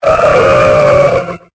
Cri de Wailmer dans Pokémon Épée et Bouclier.